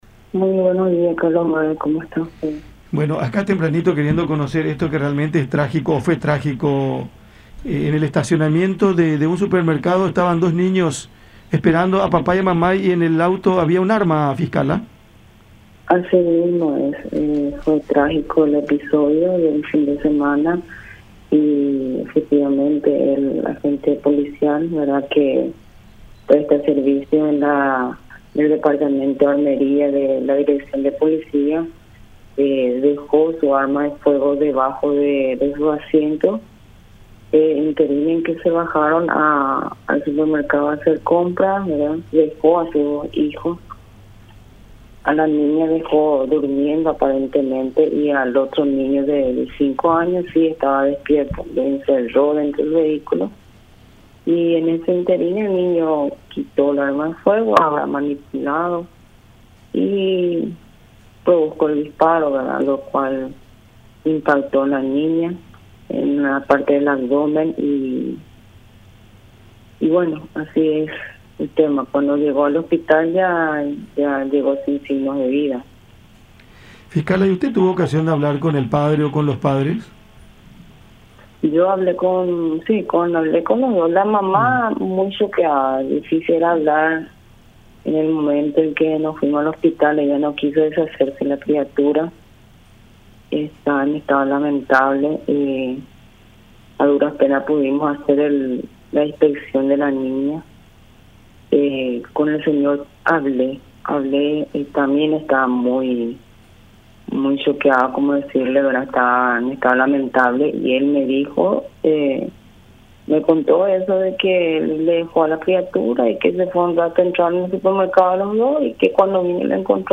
Este disparo dio en el abdomen de su hermana, de apenas dos años”, expuso la fiscal Cinthia Leiva, interviniente en el caso, en conversación con La Unión.